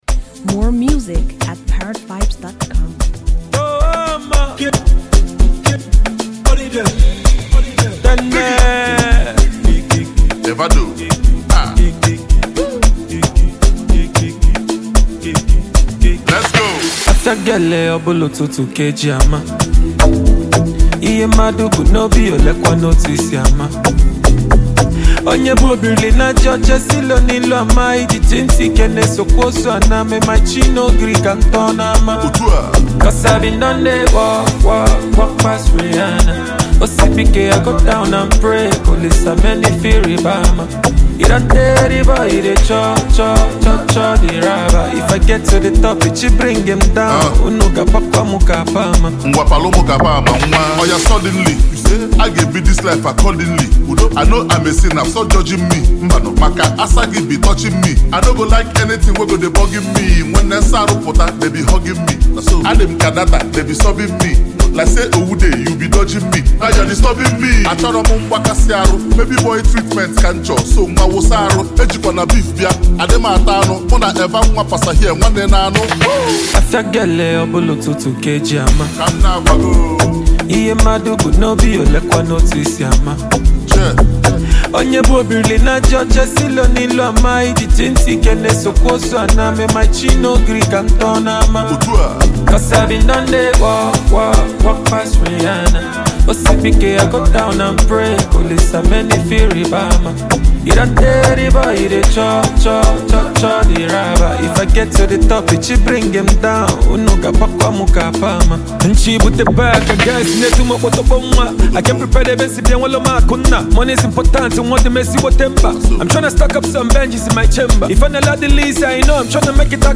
Nigerian rap